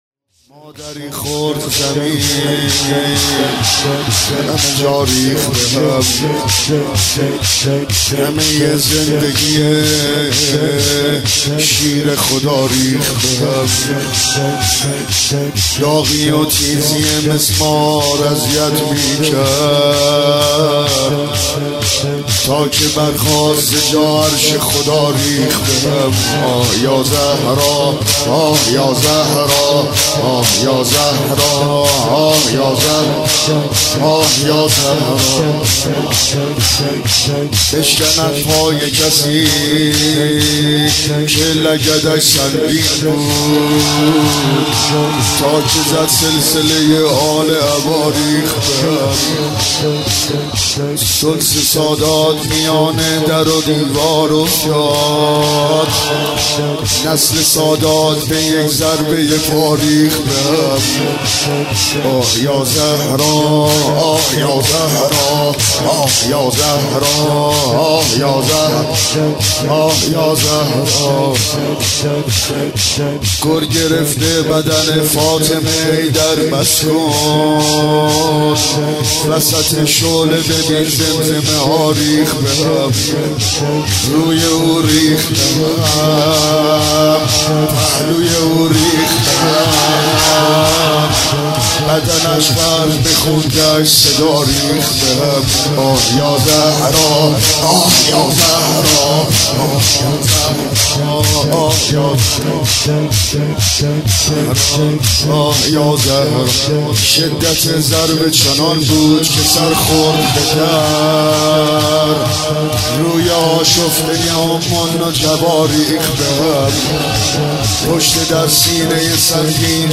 • مداحی